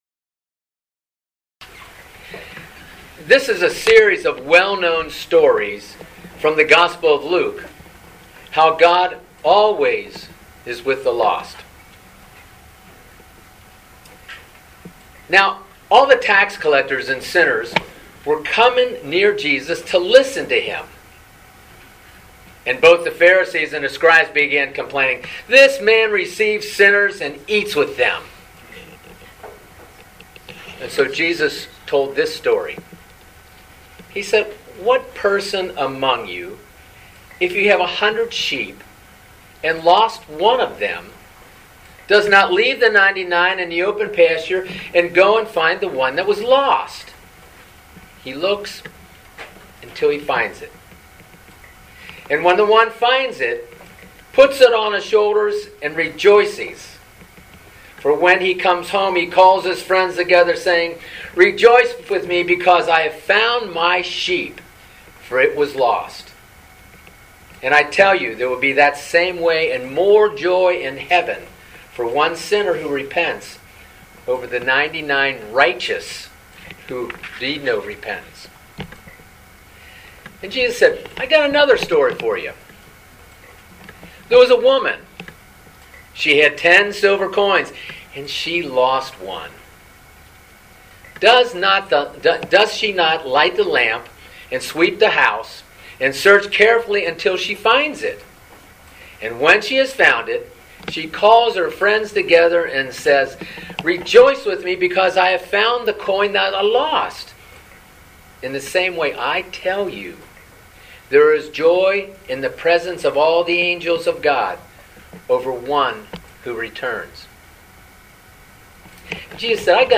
Living Beatitudes Community Homilies: Include Everyone